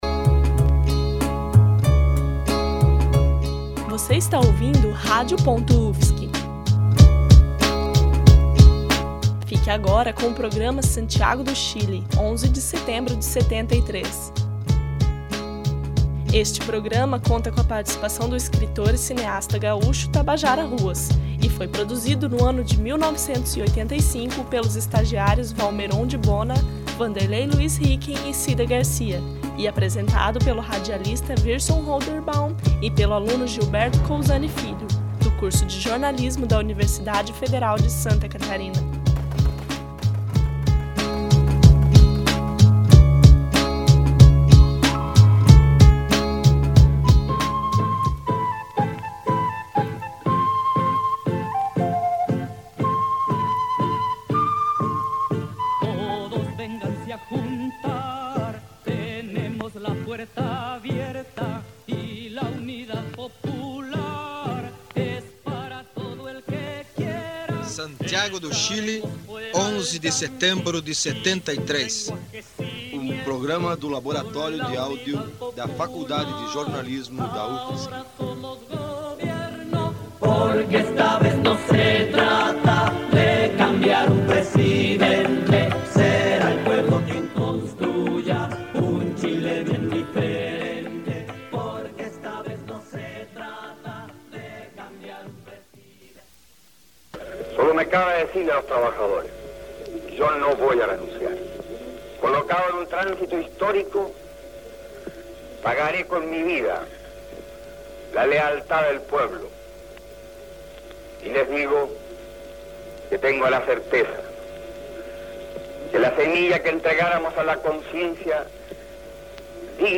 Programa radiojornalístico sobre os últimos dias de Salvador Allende na presidência do Chile. O trabalho conta com a participação do escritor e cineasta Tabajara Ruas.